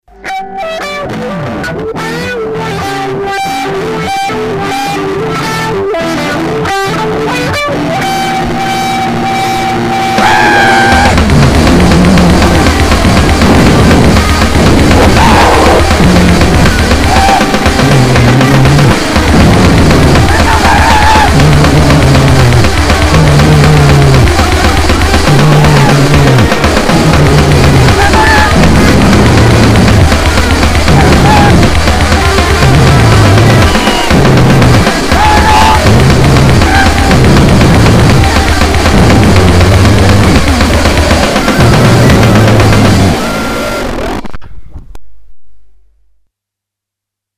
песенка в стиле Порнблюзкорэ и гуттурал вокал...